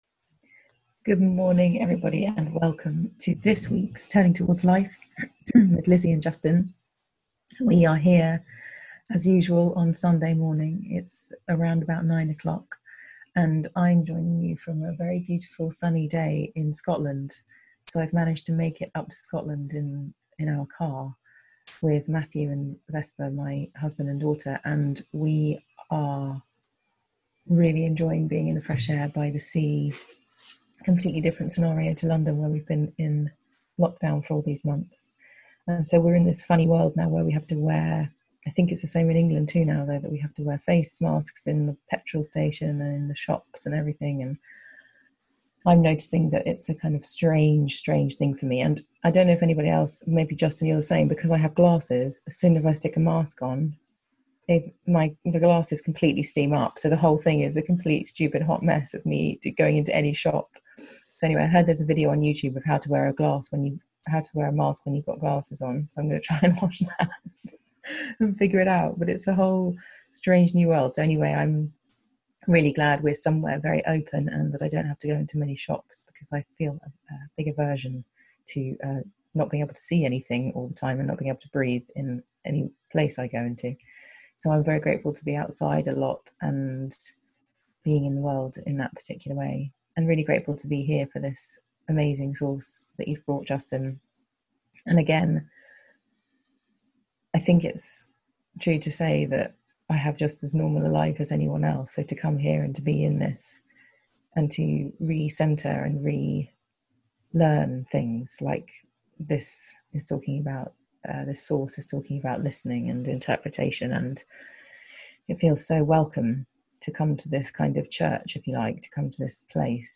Find us on FaceBook to watch live and join in the lively conversation on this episode.